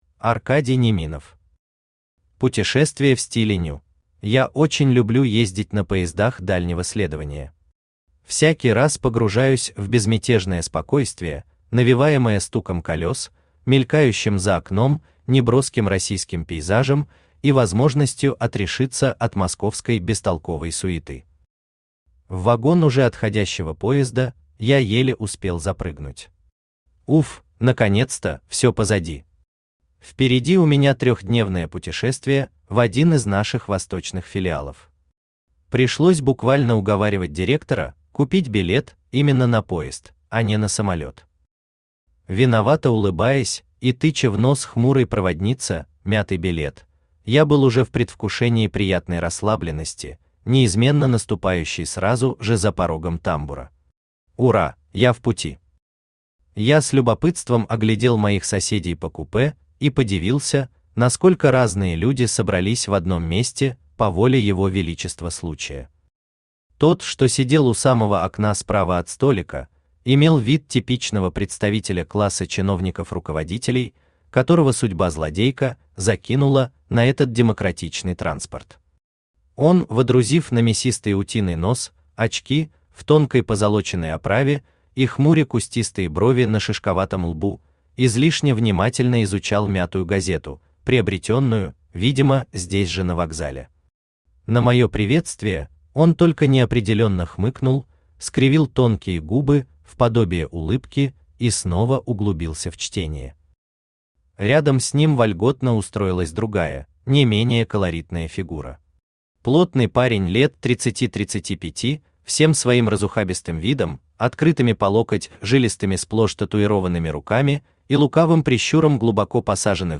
Аудиокнига Путешествие в стиле «ню» | Библиотека аудиокниг
Aудиокнига Путешествие в стиле «ню» Автор Аркадий Неминов Читает аудиокнигу Авточтец ЛитРес.